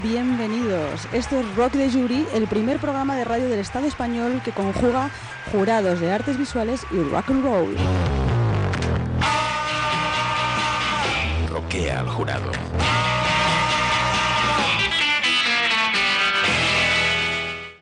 Presentació del programa
Extret del programa "El ojo crítico" de Radio Nacional de España emès a l'octubre del 2007.